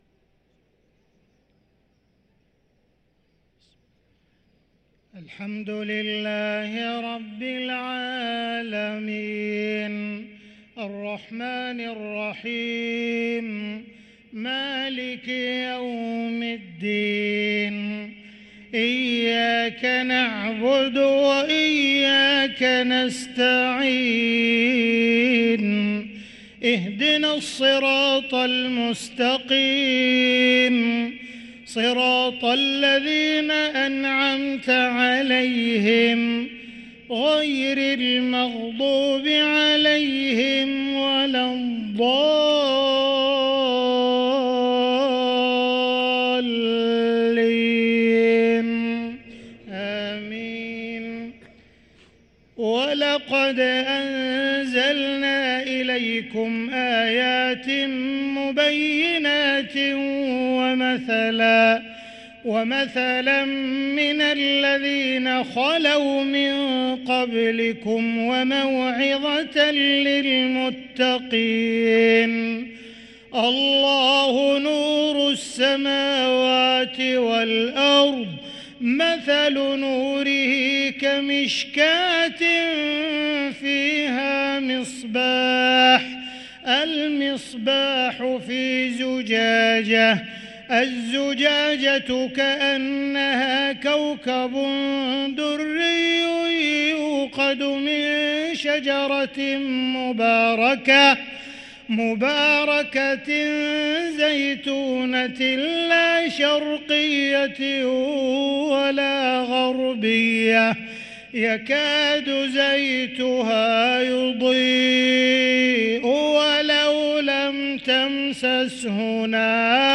صلاة المغرب للقارئ عبدالرحمن السديس 27 رمضان 1444 هـ
تِلَاوَات الْحَرَمَيْن .